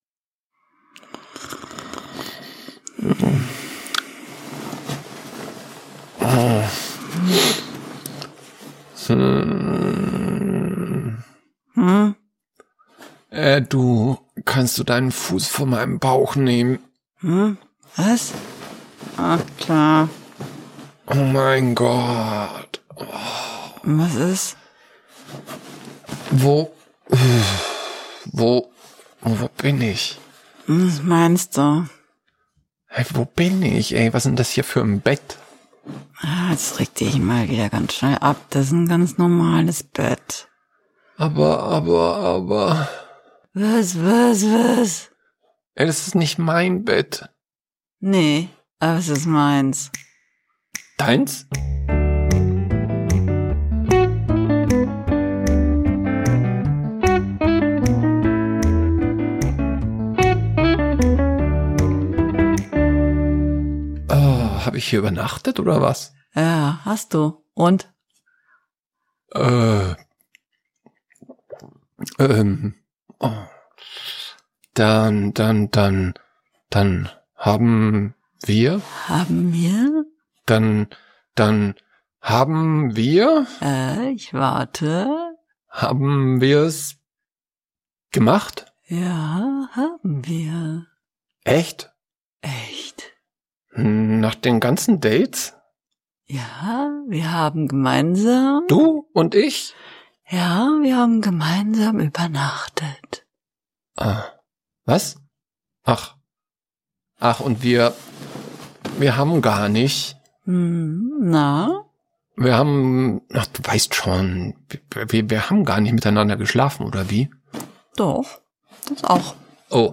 So ergeht es auch den beiden Protagonisten in unserem heutigen Hörspiel, in dem es unter anderem um Drinks, Brunnen, Ordnernamen, Affären und um Hoffnung geht.